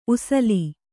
♪ usali